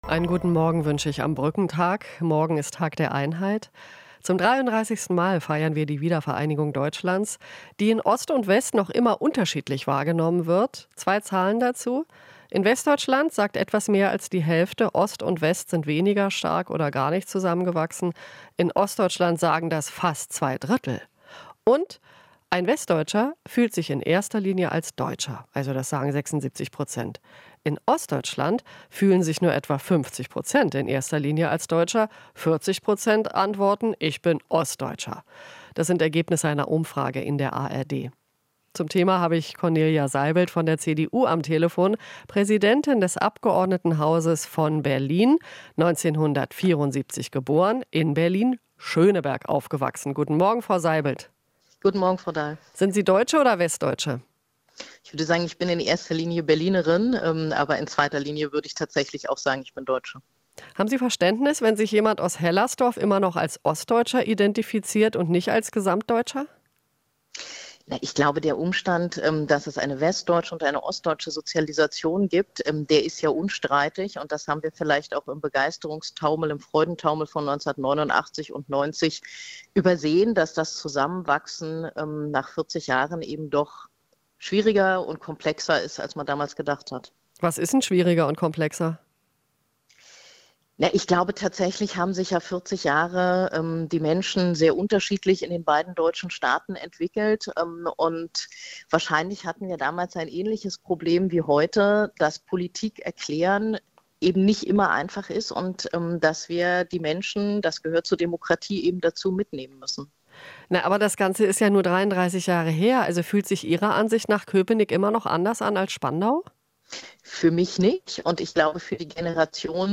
Interview - Seibeld (CDU): Politik darf nicht über Interessen der Mehrheit hinwegregieren